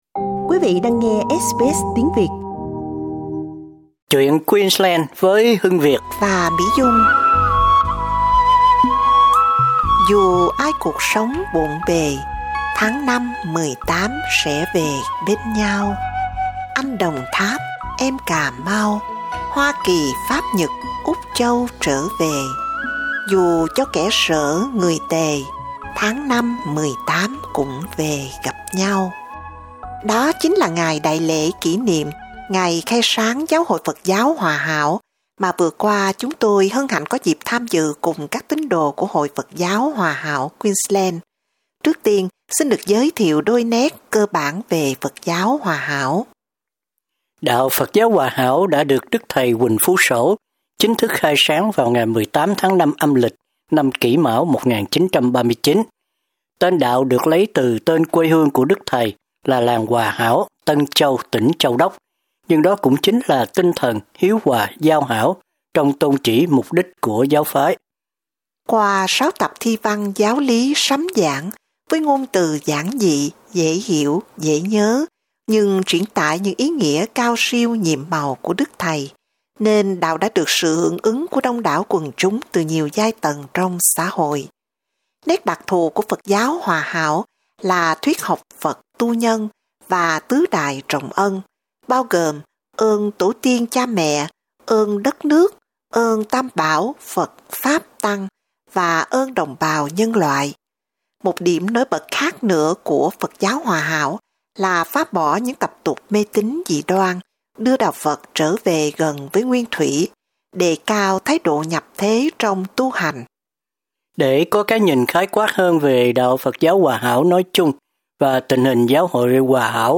cuộc mạn đàm